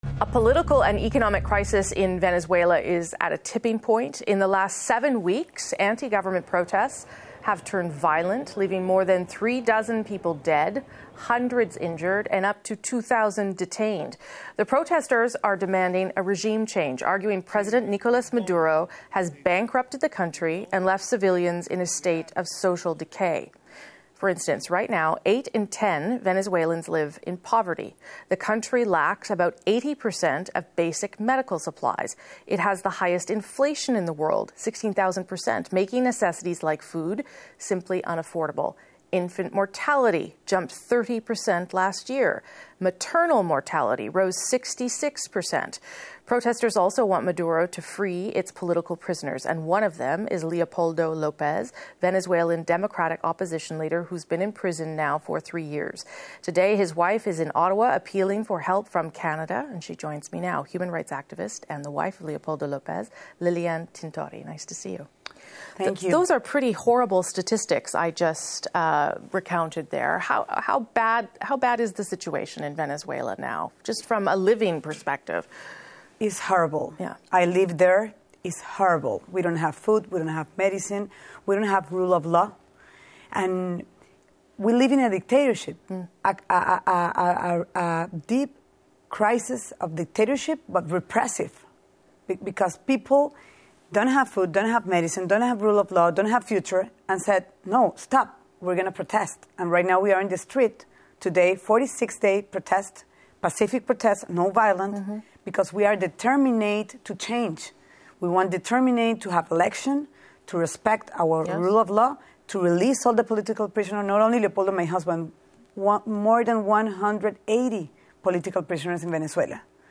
En conversación desde Caracas